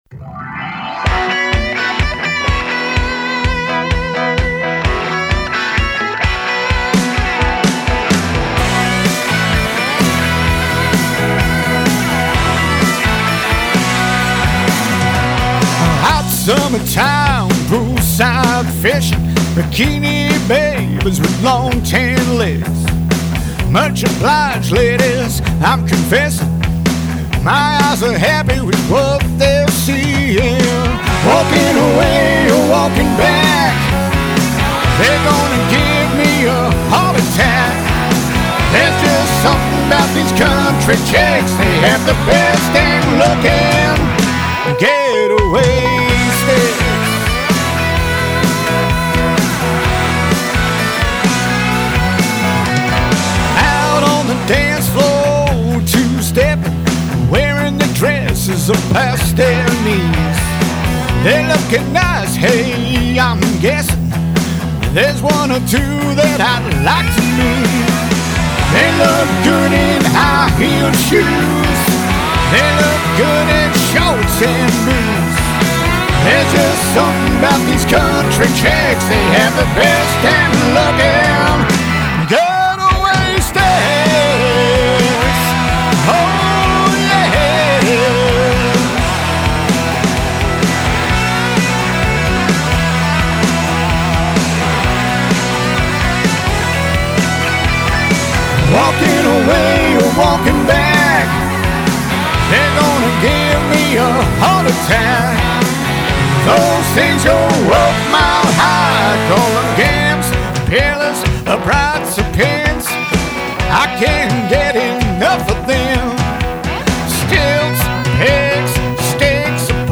rockin’ feel good track